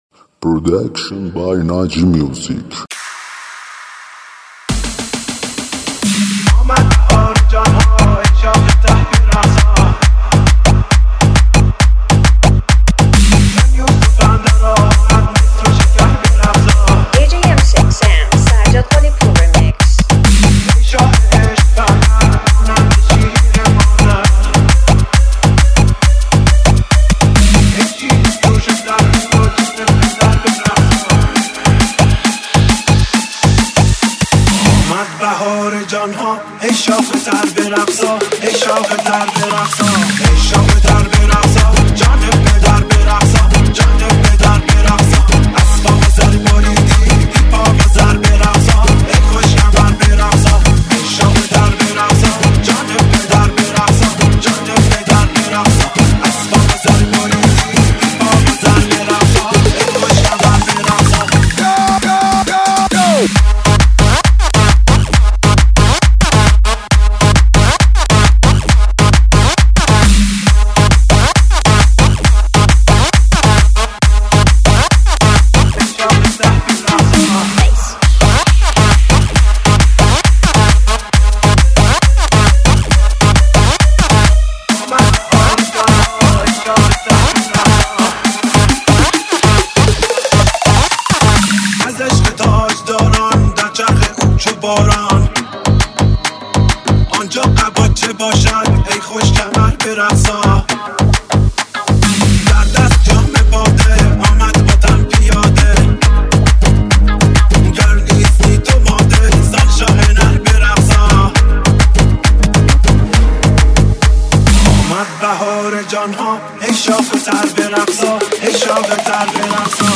دانلود ریمیکس شاد تریبال
tribal mix
آهنگ شاد تریبال مخصوص پارتی و رقص